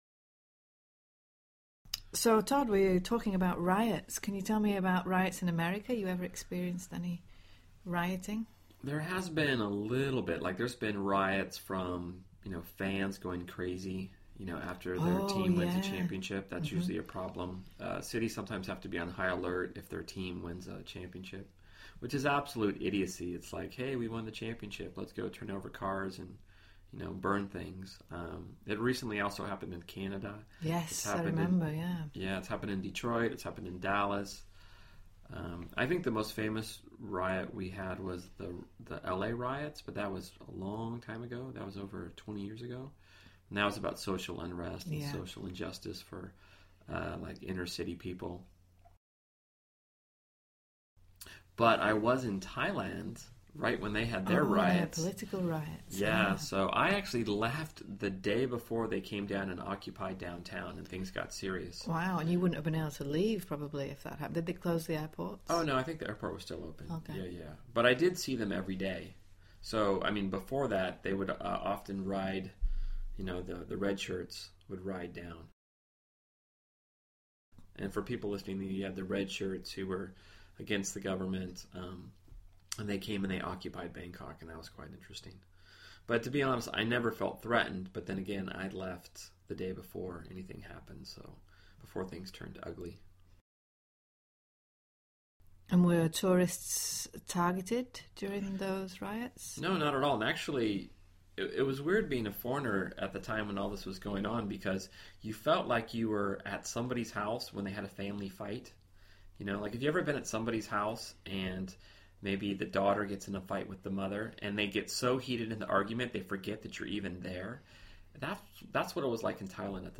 在线英语听力室英文原版对话1000个:1202 Reasons for Riots的听力文件下载,原版英语对话1000个,英语对话,美音英语对话-在线英语听力室